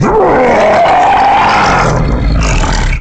Dogadon sound effect from Donkey Kong 64
Dogadon's_Godzilla_Roar_1.oga.mp3